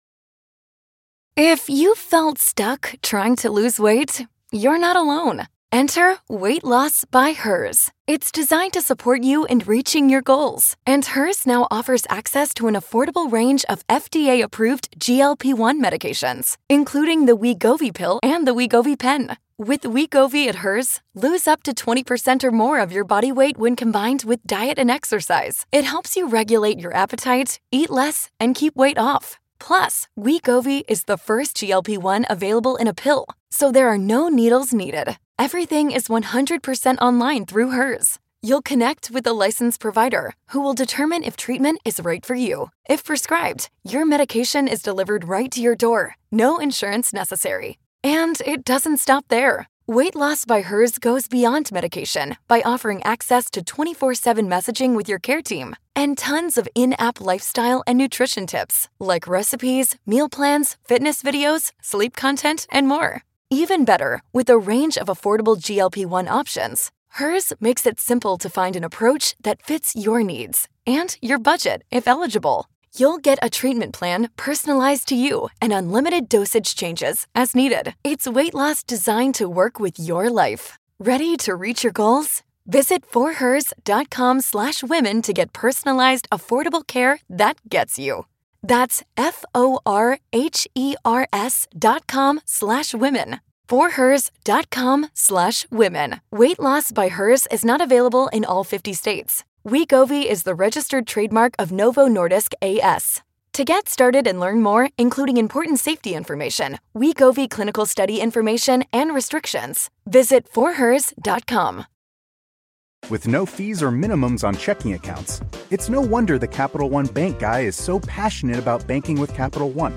In his monologue, Rushkoff reminds us that it's okay to turn to civics to avoid burnout from 24/7 political coverage.